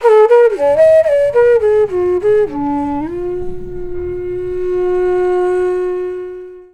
FLUTE-A12 -R.wav